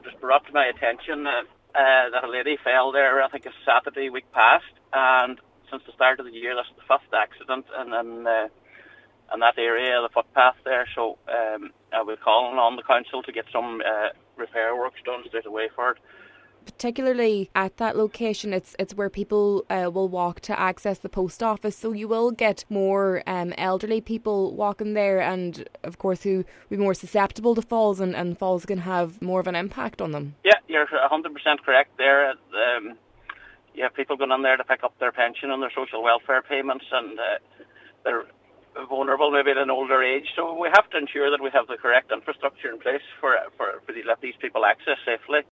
Cllr Scanlon says something needs to be done before a serious incident:
martin-scanlon-1pm.mp3